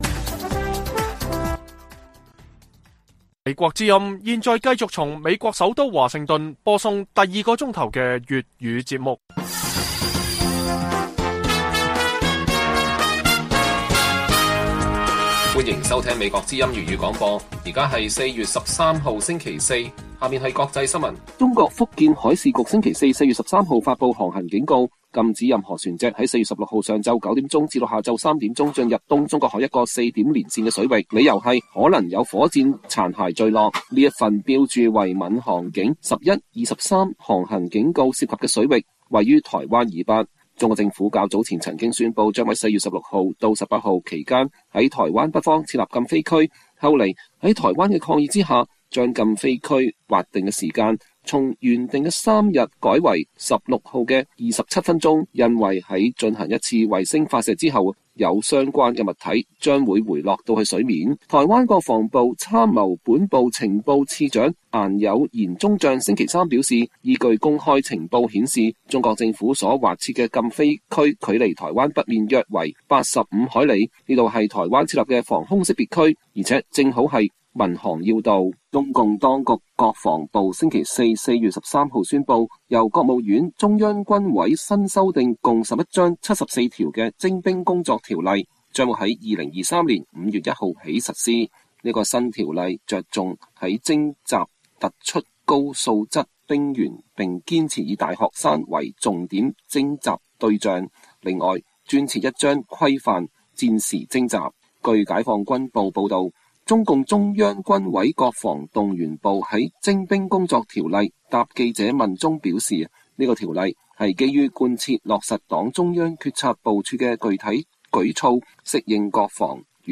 粵語新聞 晚上10-11點: 英國MI5指中國特務企圖以第三國公民身份免簽證入境英國